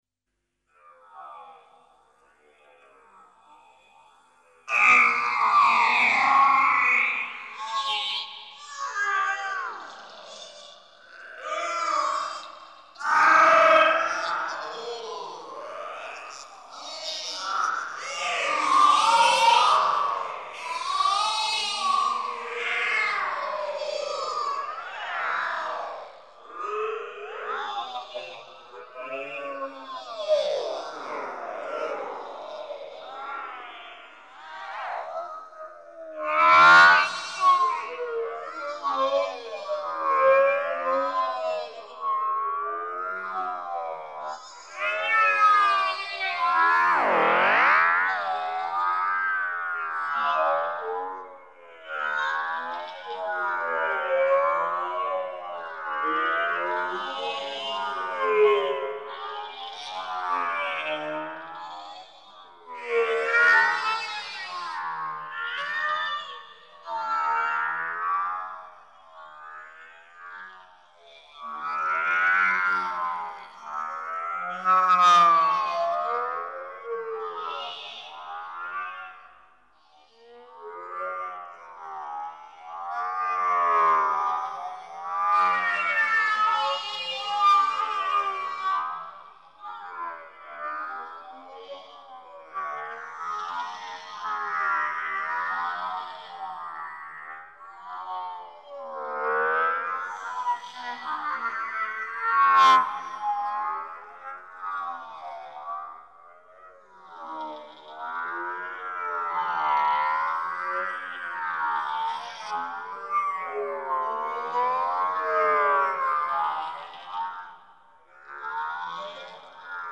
prog-rock